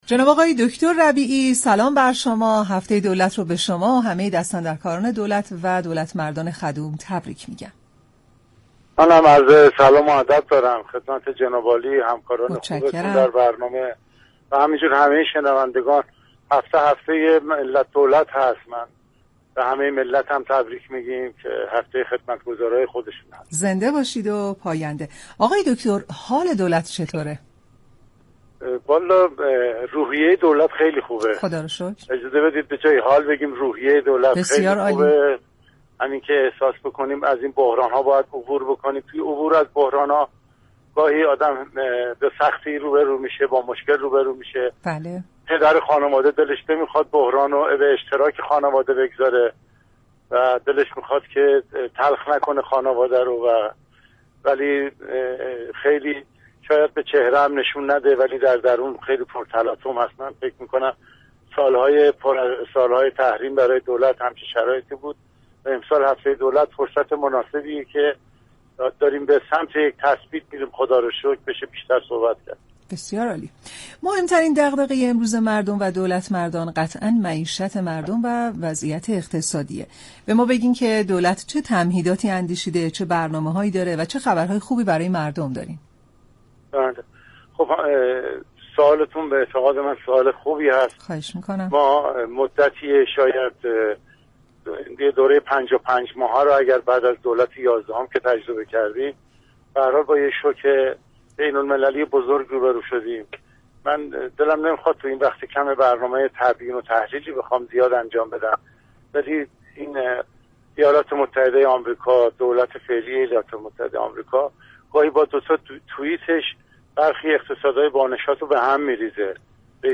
«علی ربیعی» سخنگوی دولت در پنجمین روز از هفته دولت در برنامه «سلام صبح بخیر» رادیو ایران گفت : نمی گویم ارزانی در كشور ایجاد شده بلكه از گرانی بیشتر جلوگیری شده است